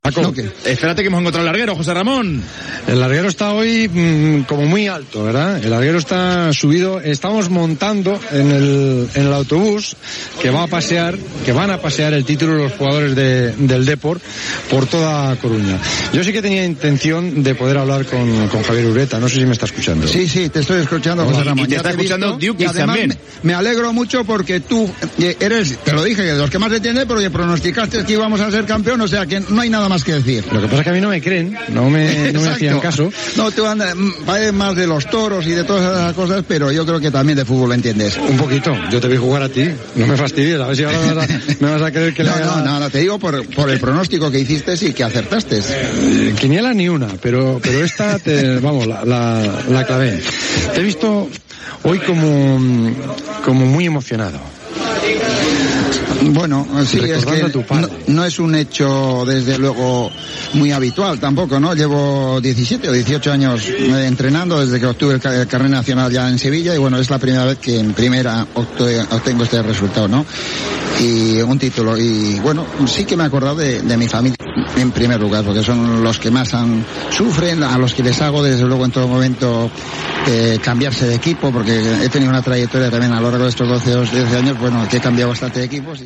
Transmissió de la celebració del títol de lliga de primera divsió de futbol masculí per part del Deportivo de La Coruña, a la temporada 1999-2000.
Esportiu